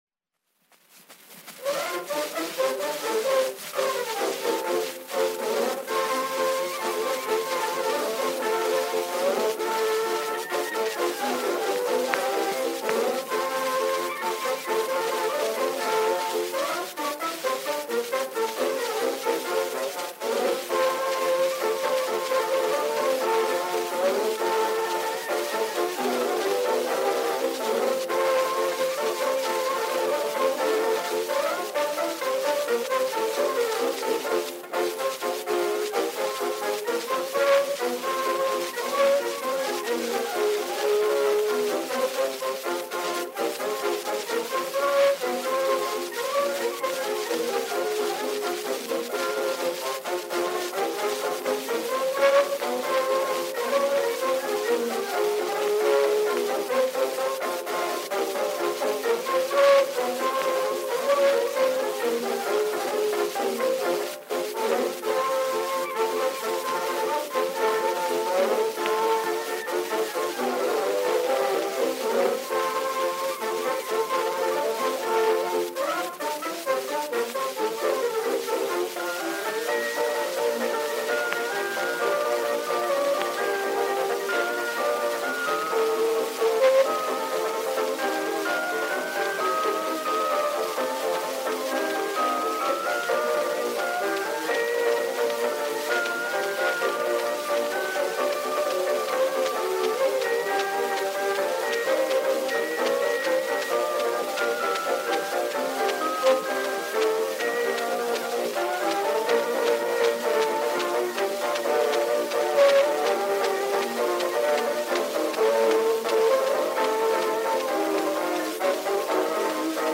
Dance music.
Popular instrumental music—1911-1920.
Dance orchestra music.